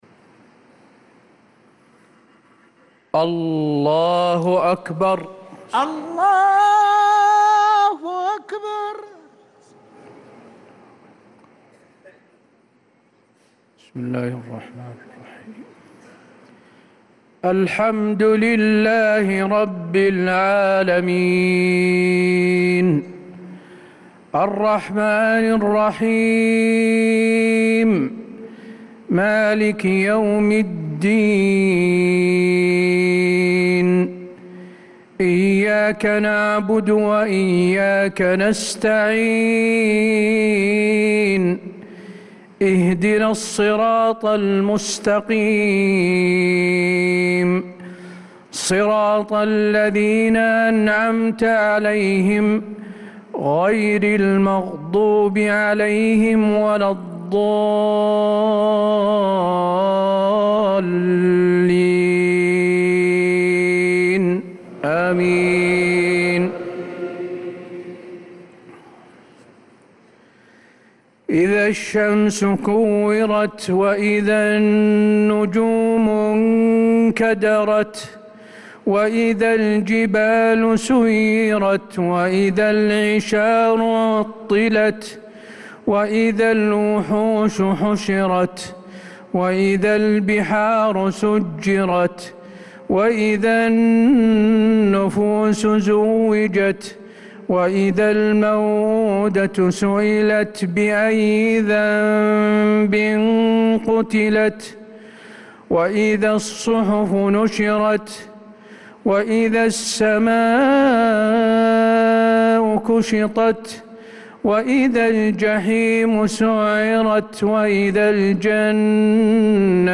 صلاة العشاء للقارئ حسين آل الشيخ 22 ربيع الأول 1446 هـ
تِلَاوَات الْحَرَمَيْن .